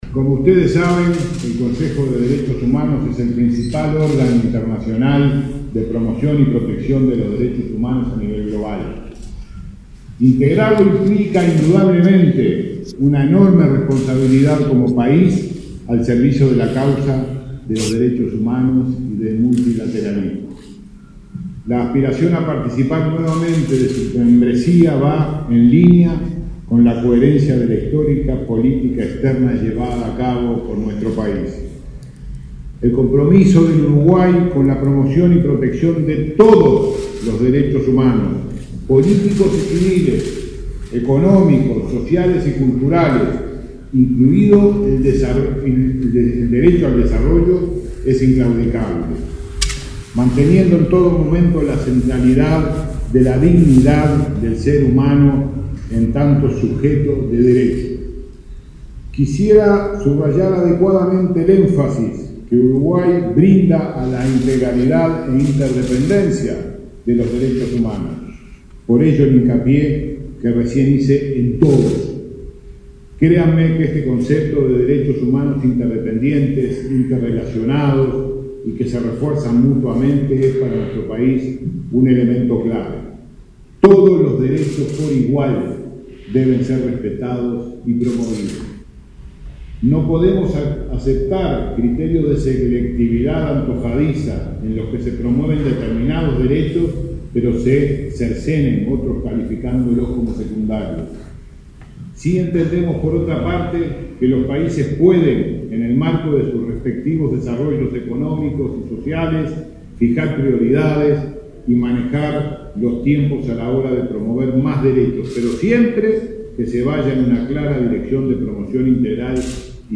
Gobierno presentó ante Naciones Unidas su candidatura a integrar Consejo de Derechos Humanos en 2019 – 2021. En el acto, el canciller Rodolfo Nin Novoa dijo que “el compromiso de Uruguay con la promoción y protección de todos los derechos es inclaudicable” y que considera al ser humano como sujeto de derecho. Destacó los valores de diálogo, negociación y consenso como forma de contribuir a la institucionalidad del organismo.